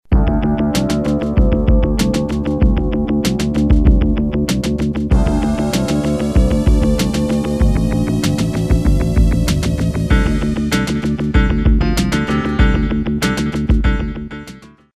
Documentary 53b